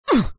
hurt.wav